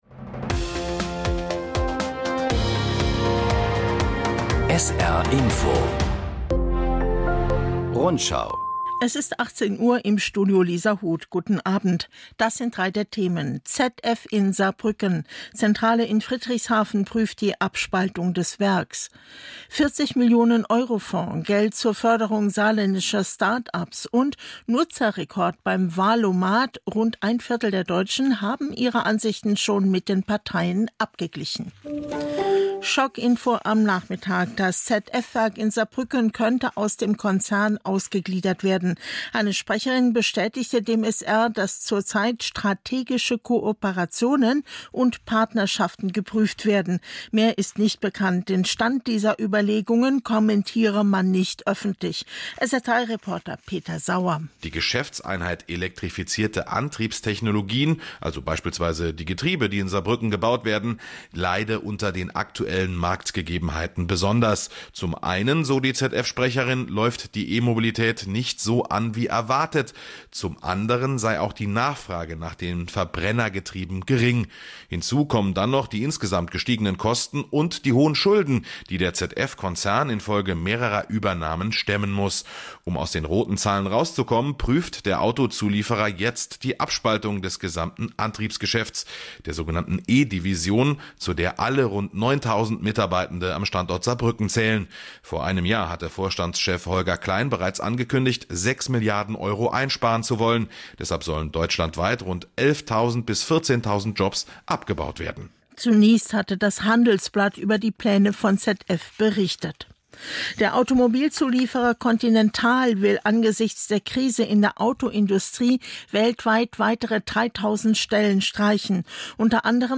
… continue reading 5 episodes # Nachrichten